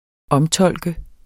Udtale [ -ˌtʌlˀgə ]